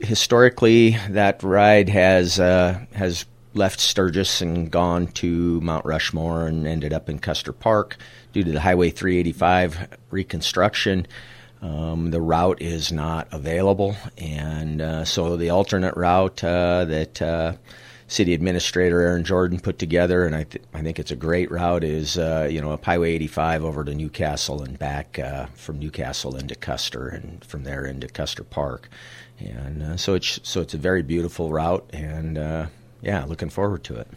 Leading the pack this year, Sturgis Mayor Kevin Forrester, who talks about this year’s route.